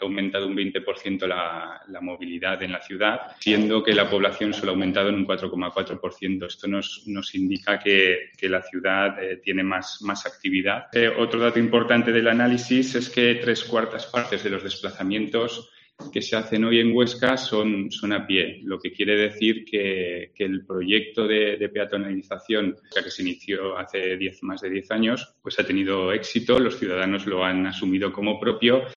Iván Rodríguez es el concejal de Urbanismo